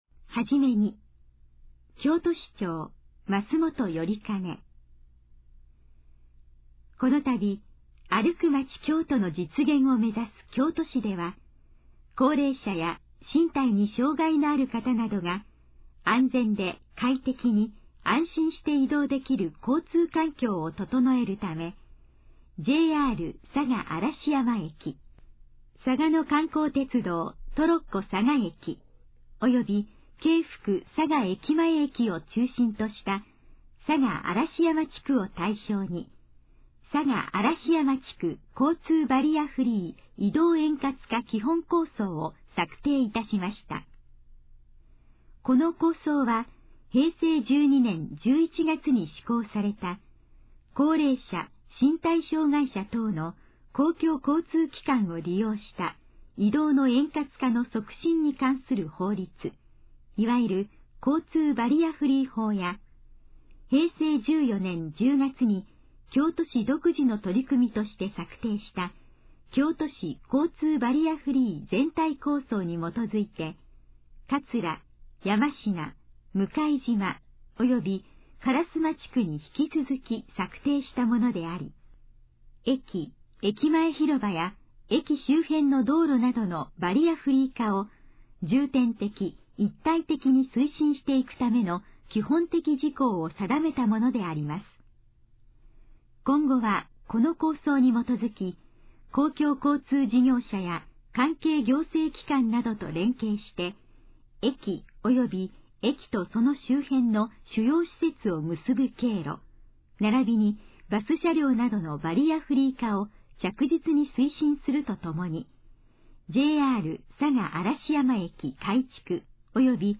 このページの要約を音声で読み上げます。
ナレーション再生 約339KB